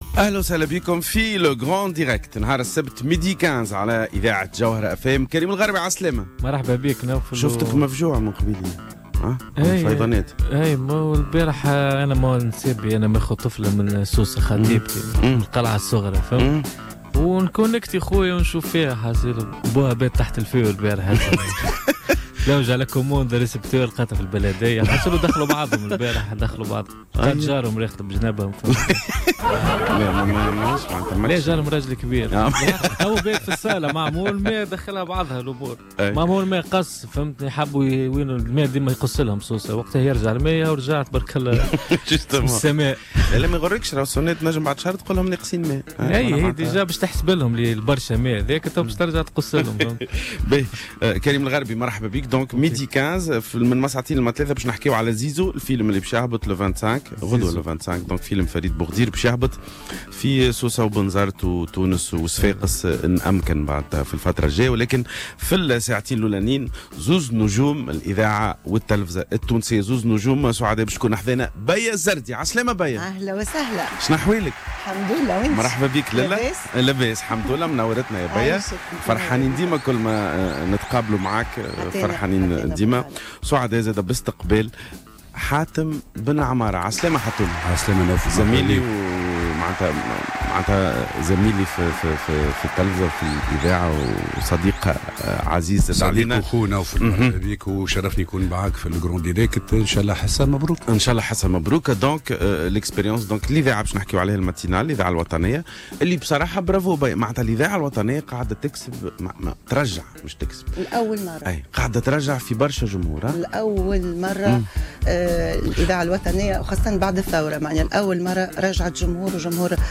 Le Grand direct تقديم نوفل الورتاني وكريم الغربي.
في بث مباشر من استوديوهاتنا في تونس العاصمة بداية من الساعة منتصف النهار